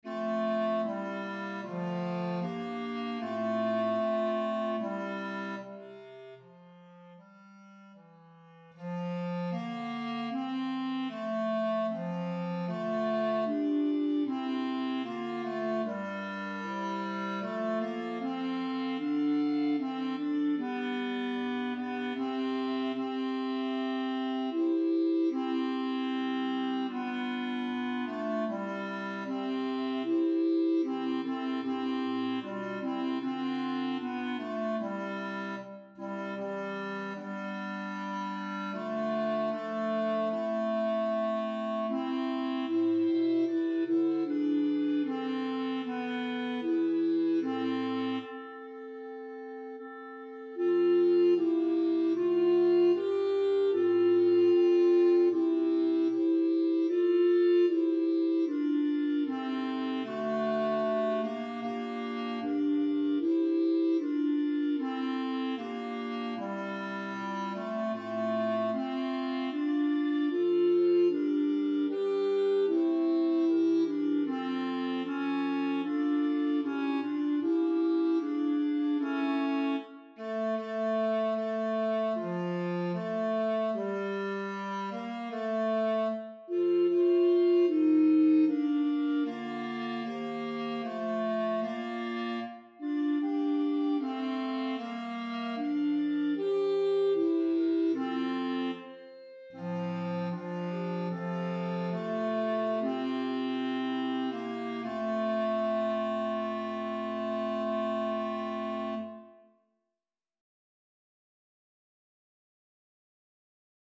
5_Benedictus_op_83_RR_Tenor.mp3